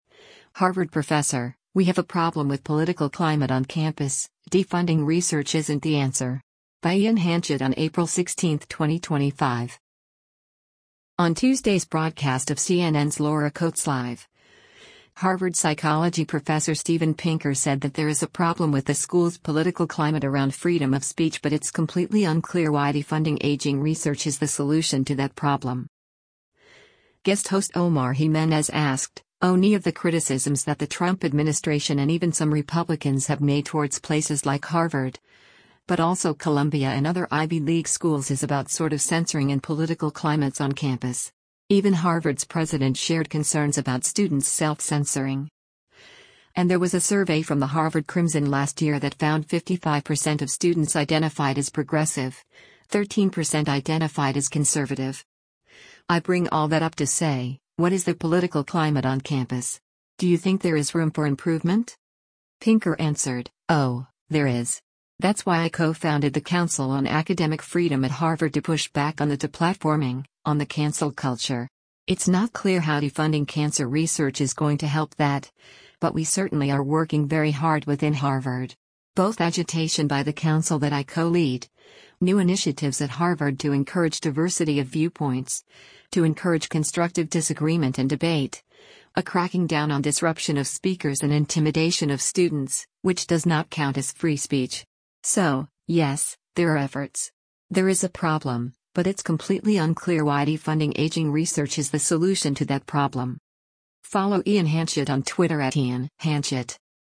On Tuesday’s broadcast of CNN’s “Laura Coates Live,” Harvard Psychology Professor Steven Pinker said that “There is a problem” with the school’s political climate around freedom of speech “but it’s completely unclear why defunding aging research is the solution to that problem.”